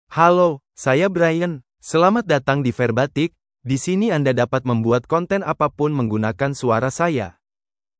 BrianMale Indonesian AI voice
Brian is a male AI voice for Indonesian (Indonesia).
Voice sample
Listen to Brian's male Indonesian voice.
Brian delivers clear pronunciation with authentic Indonesia Indonesian intonation, making your content sound professionally produced.